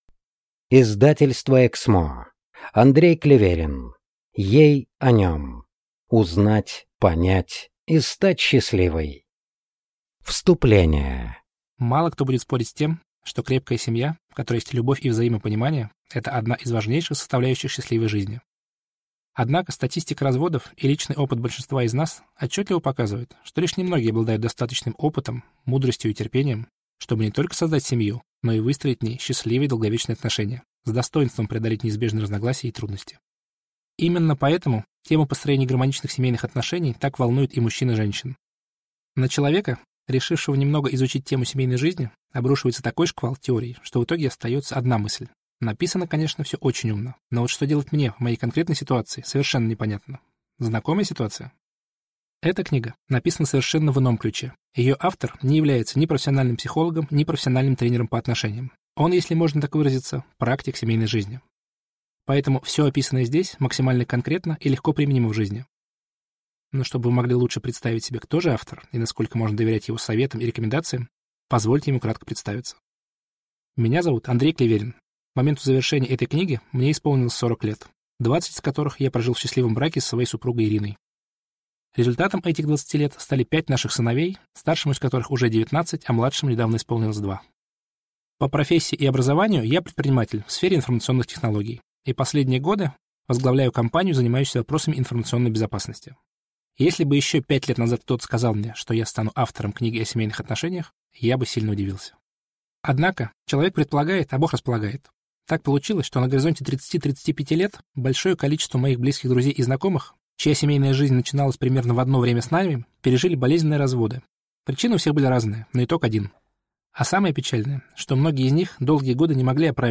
Аудиокнига Ей о нем. Узнать, понять и стать счастливой | Библиотека аудиокниг